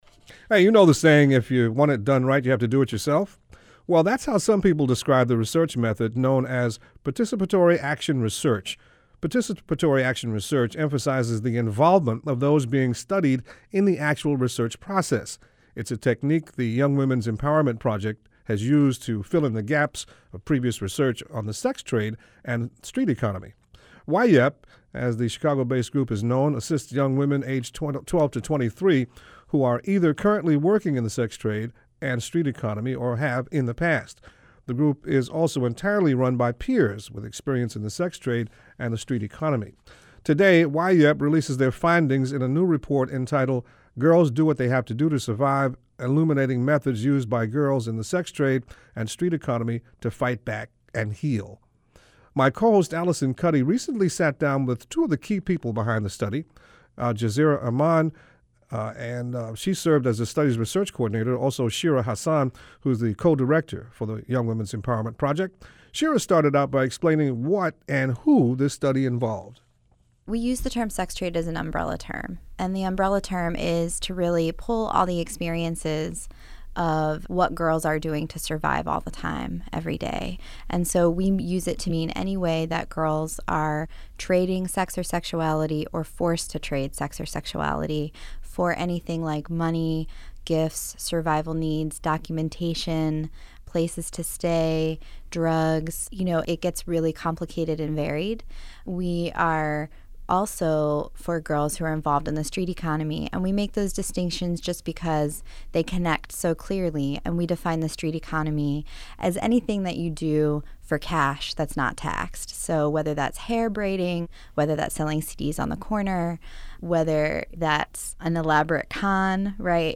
NPR radio story about our 2009 research on how we fight back and heal